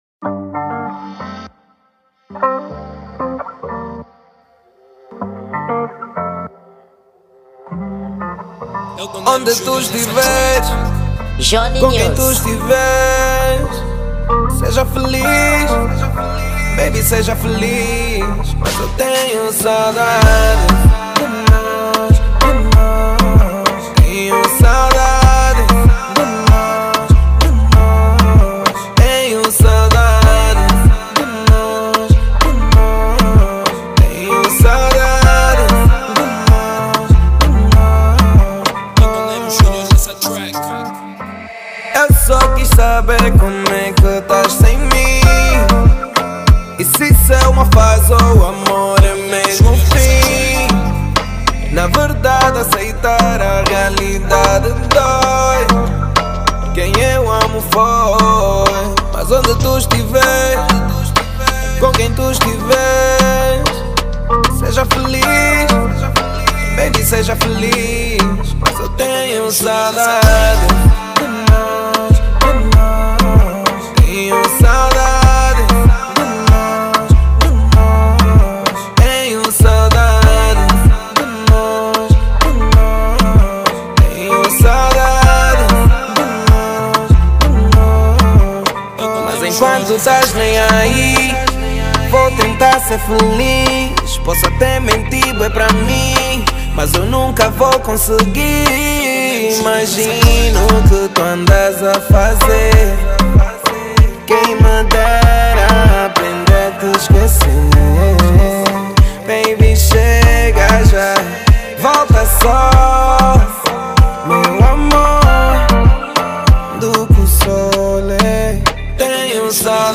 Gênero: Rap